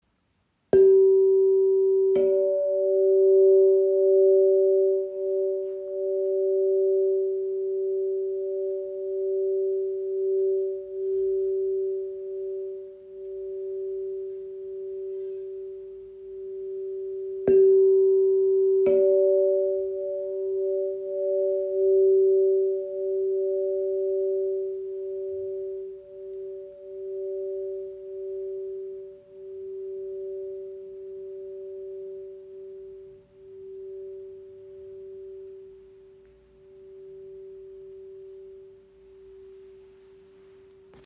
Die Wave G/D in 432 Hz ist ein handgefertigtes Klanginstrument in der heiligen Quinte das Harmonie, Ruhe und Energiefluss schenkt.
Der Klang breitet sich weich und klar im Raum aus, ohne zu dominieren, und lädt Dich ein, langsamer zu werden, tiefer zu atmen und wieder bei Dir selbst anzukommen.